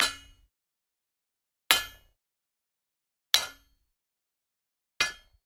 Звуки гантелей
Звук ударов гантелей